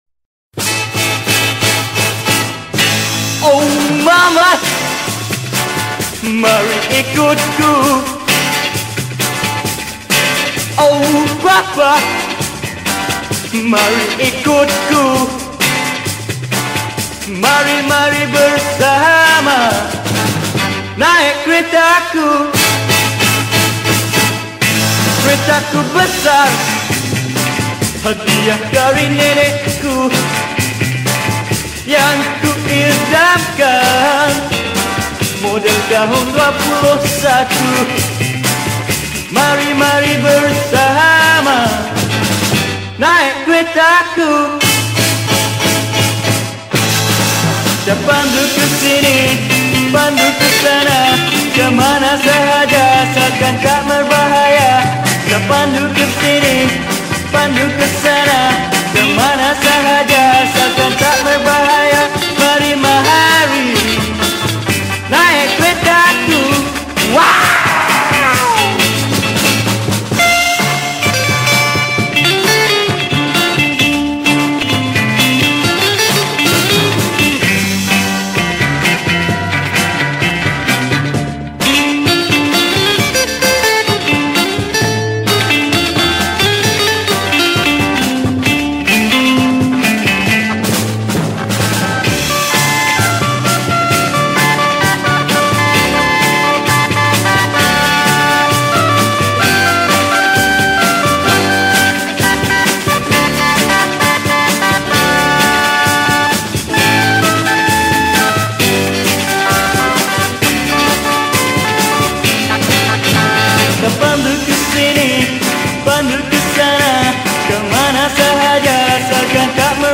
Pop Yeh Yeh